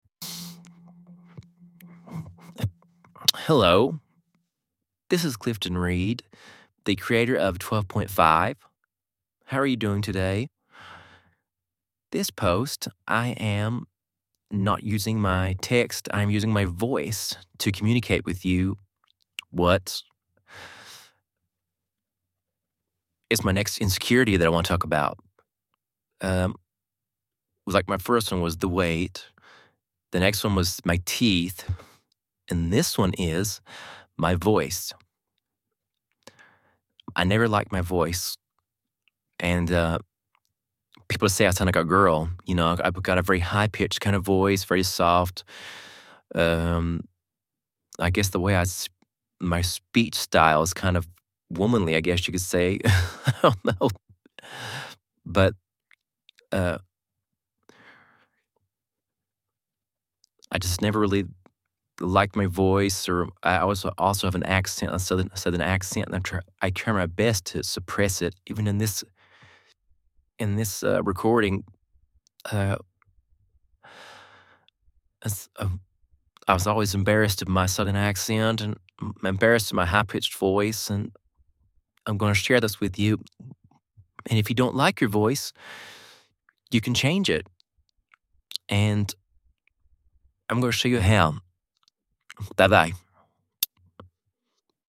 I used a service by Eleven Labs.